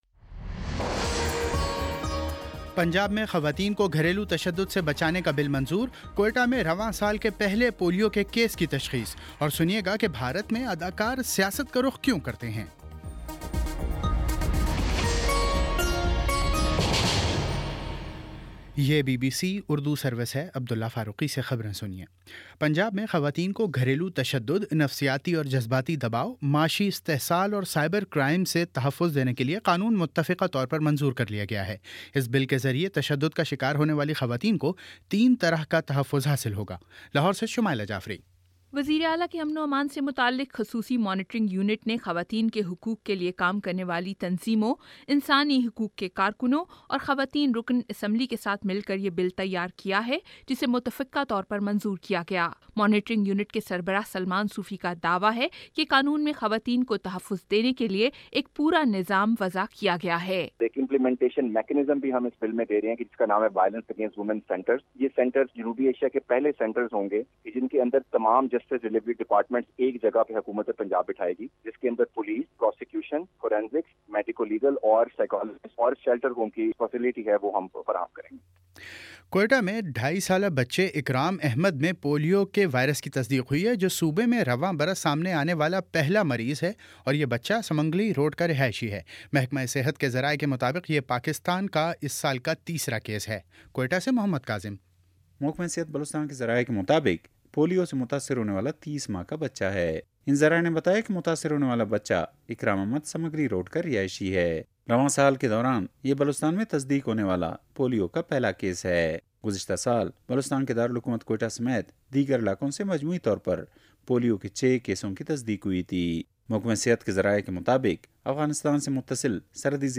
فروری 24 : شام پانچ بجے کا نیوز بُلیٹن